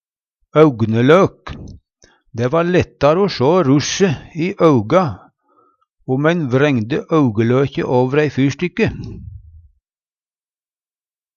augelok - Numedalsmål (en-US)
Høyr på uttala Ordklasse: Substantiv inkjekjønn Attende til søk